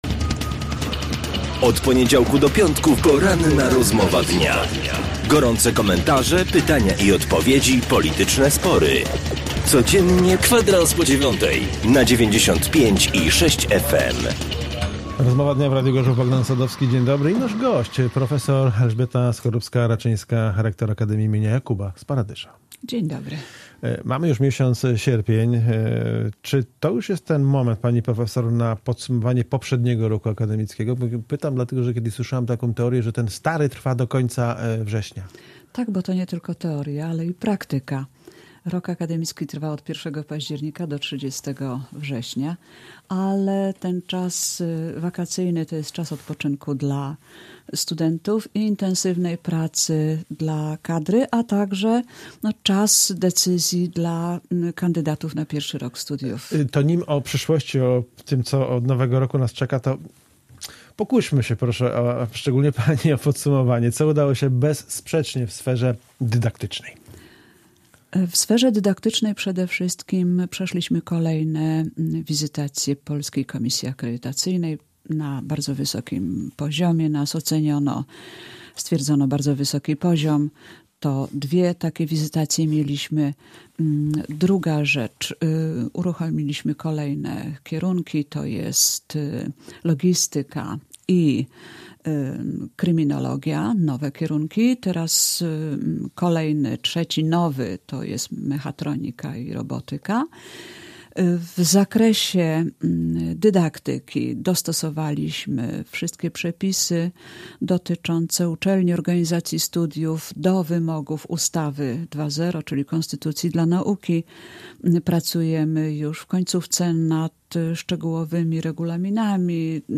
Audycje Gość na 95,6FM